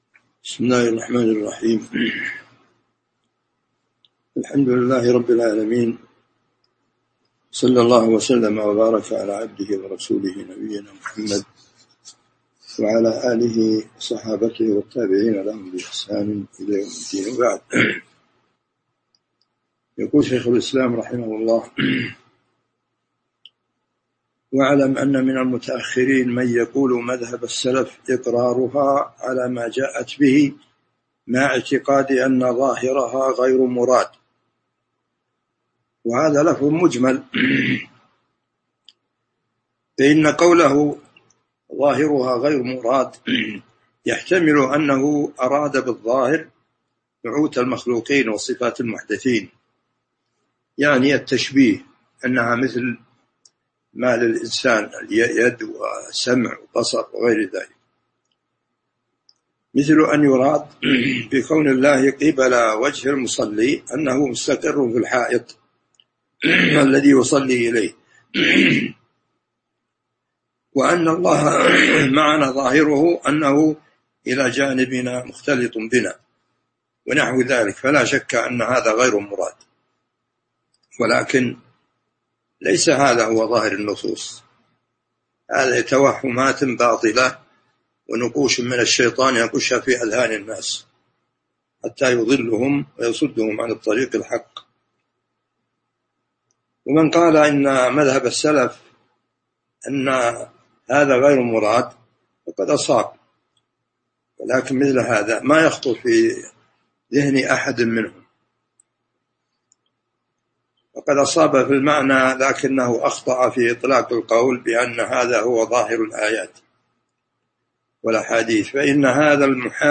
تاريخ النشر ٨ ذو القعدة ١٤٤٢ هـ المكان: المسجد النبوي الشيخ